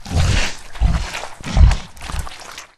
boar_eat_1.ogg